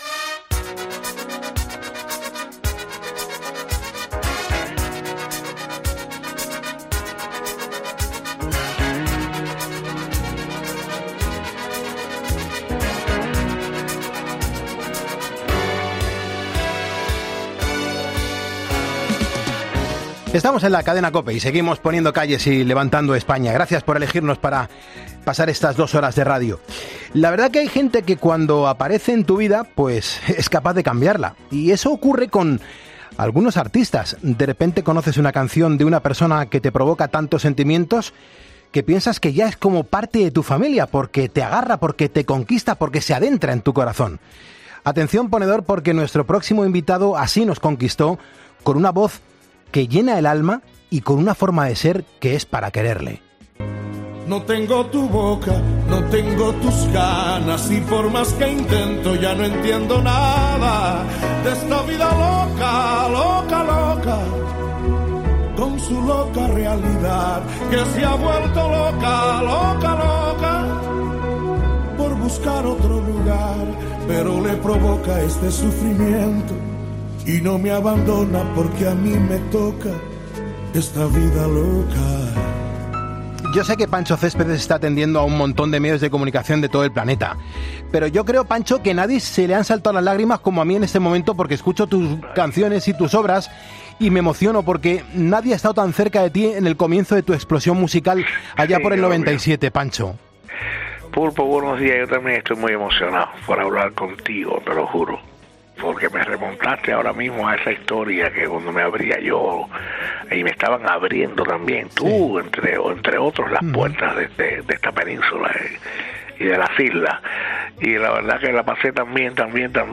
Con una voz que llena el alma y con una forma de ser que es para quererle.